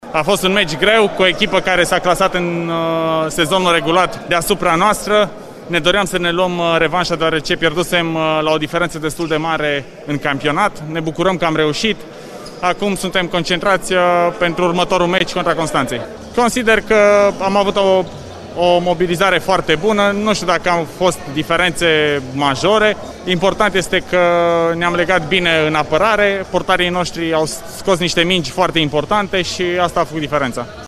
a vorbit și el după meciul câștigat în această după amiază